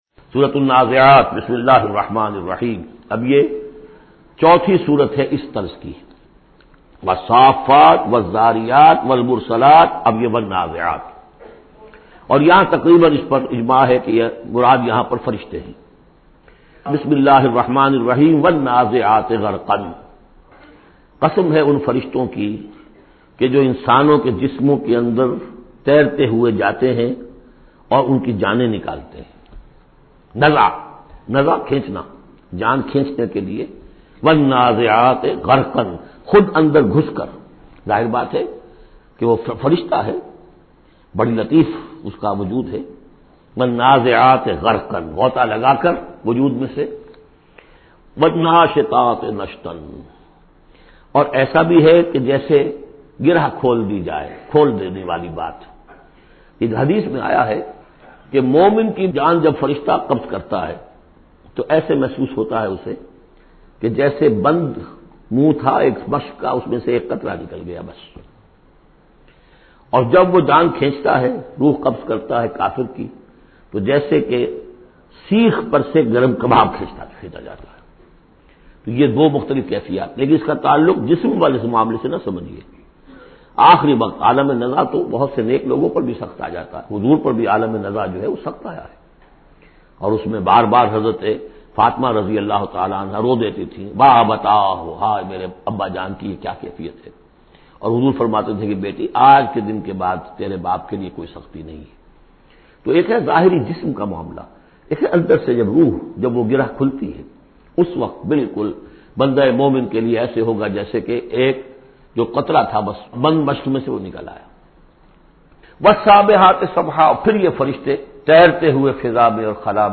Surah Naziat Tafseer by Dr Israr Ahmed
Surah Naziat is 79 chapter of Holy Quran. Listen online mp3 tafseer of Surah Naziat in the voice of Dr Israr Ahmed.